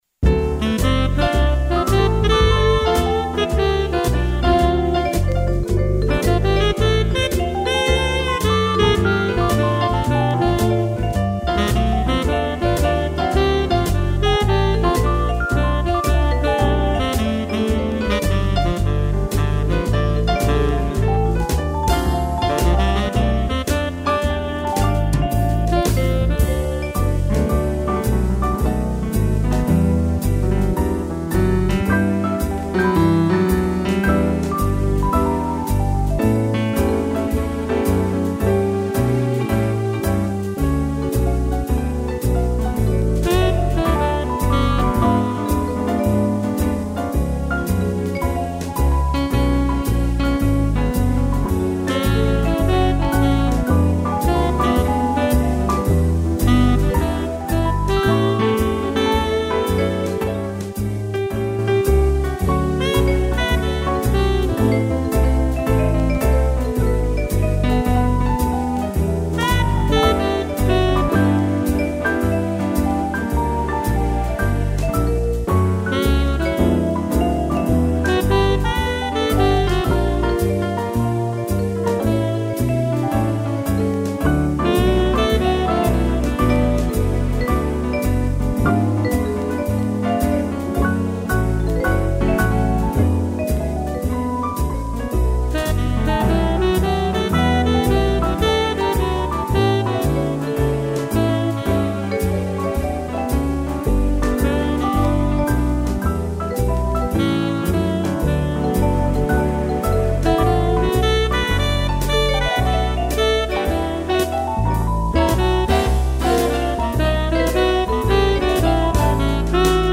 piano, vibrafone e sax
(instrumental)